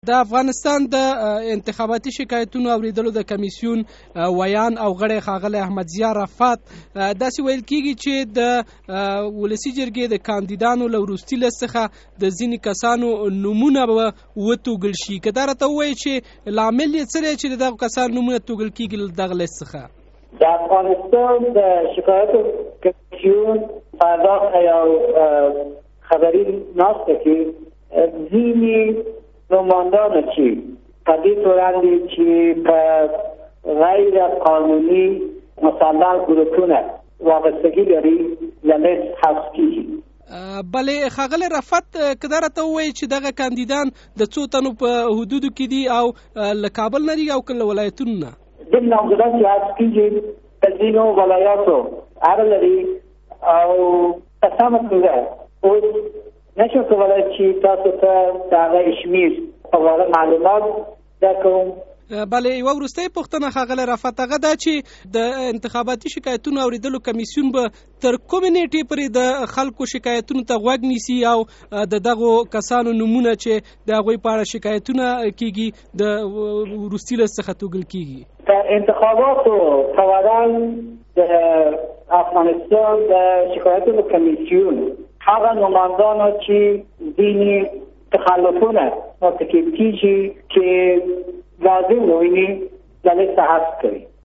مركه